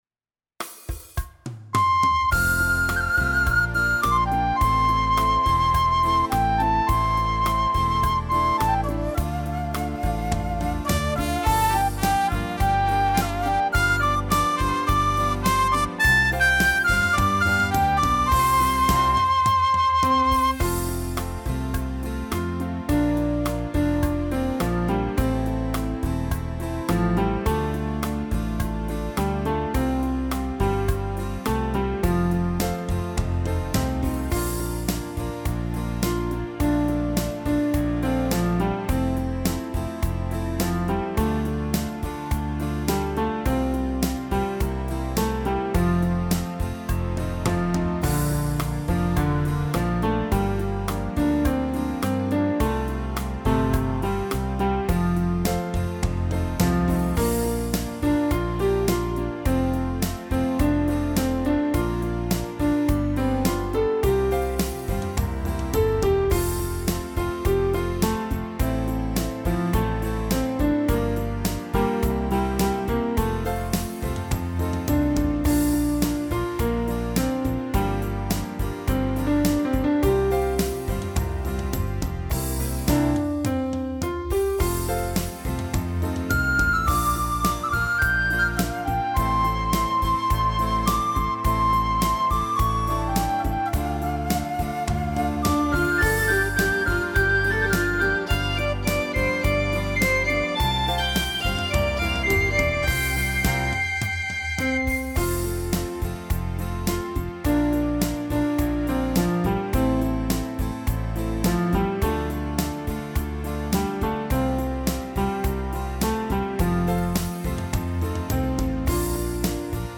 •   Beat  01.